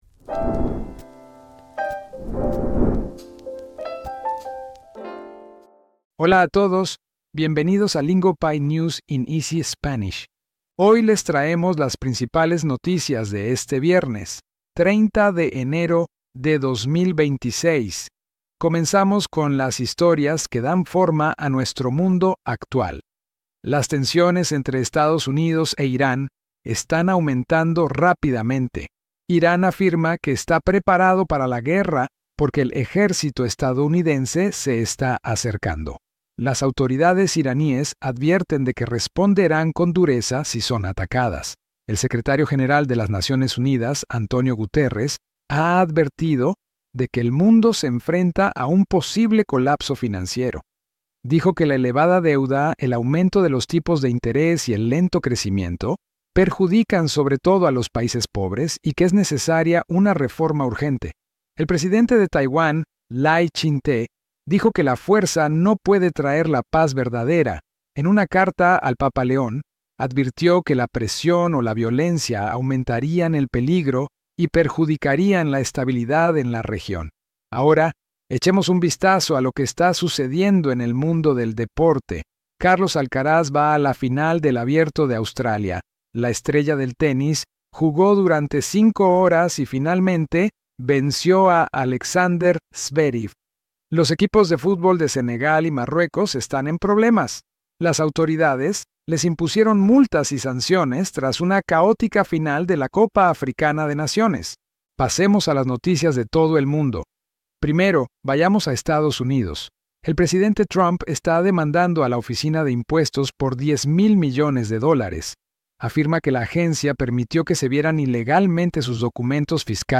Lingopie's News in Easy Spanish keeps you informed through real global headlines delivered in clear, beginner-friendly Spanish so you can follow along without the stress.